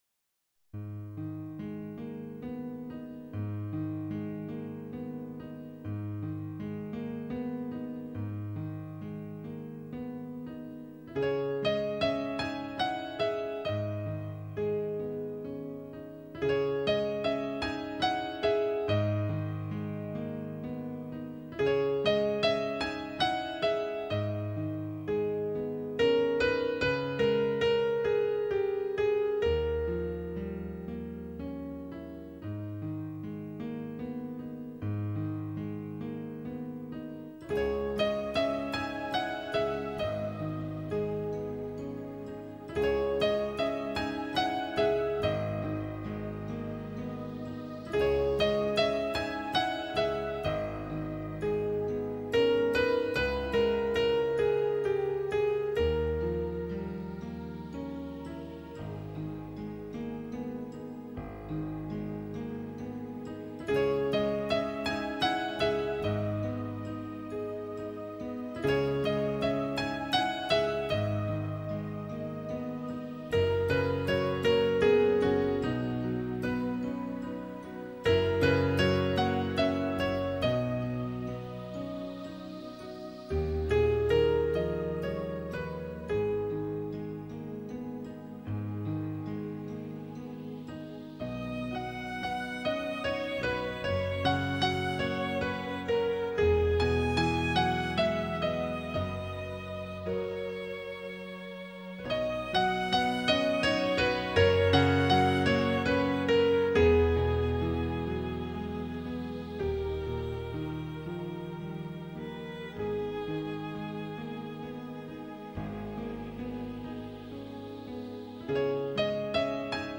【素材】Unit 8 Have you read Treasure Island yet第1课时——bgmusic.mp3